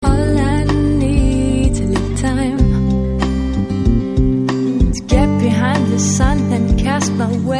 • Electronic Ringtones